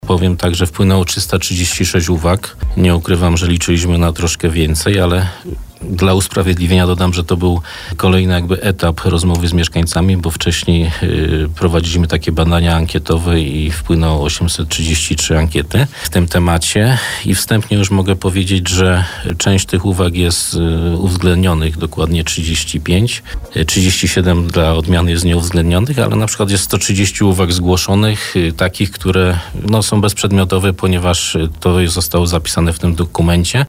– To kilkuset stronnicowy dokument. Może nie każdy z Państwa miał czas, żeby dokładnie się z nim zapoznać, ale każda uwaga jest dla nas cenna i za każdą uwagę bardzo dziękuję. Z uwag, które uwzględniliśmy na przykład to dodatkowy przystanek na ul. Piastowskiej, w stronę Hulanki […], czy częstotliwość kursowania autobusu linii nr 6 [bielskiego MZK] – mówił na naszej antenie Piotr Kucia, zastępca prezydenta Bielska-Białej.